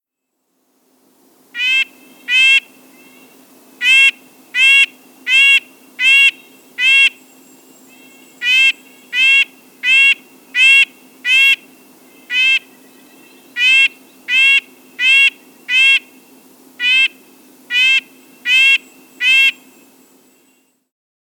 Red-breasted Nuthatch
How they sound: Red-breasted Nuthatches sing a fast series of nasal, hornlike notes that sound like yank-yank.